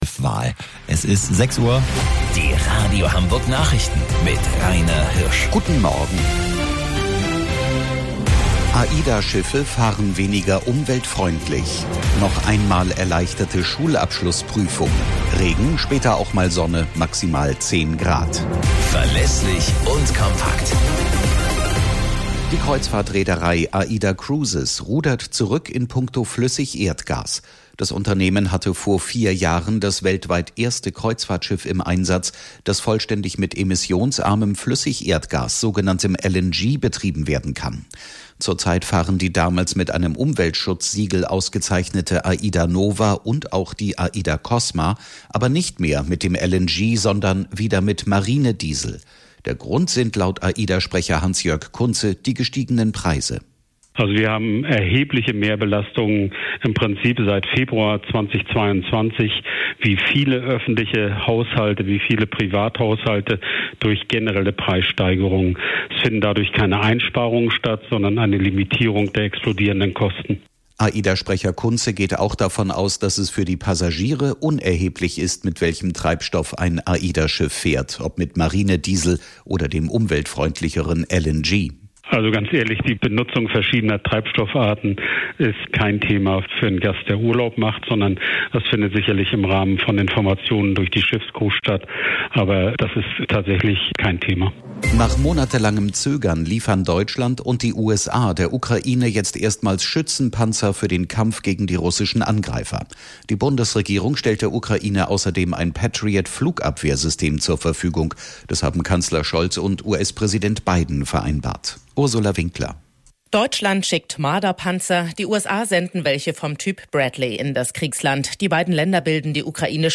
Radio Hamburg Nachrichten vom 17.06.2022 um 09 Uhr - 17.06.2022